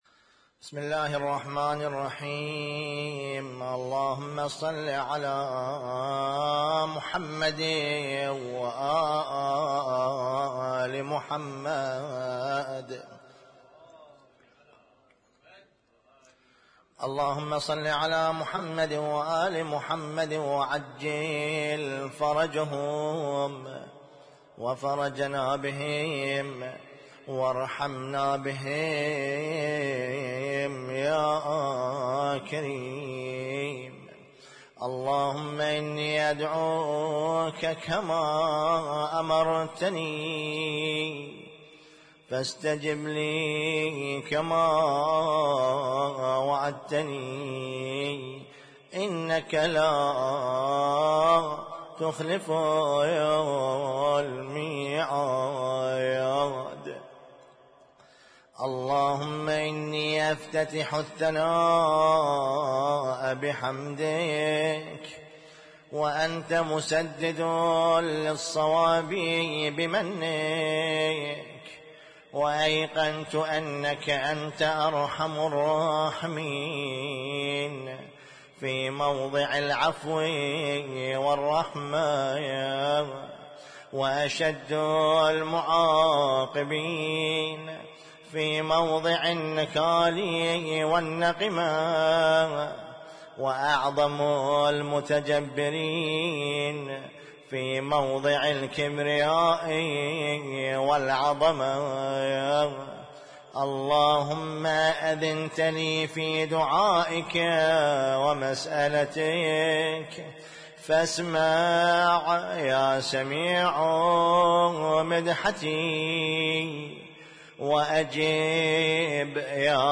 اسم التصنيف: المـكتبة الصــوتيه >> الادعية >> دعاء الافتتاح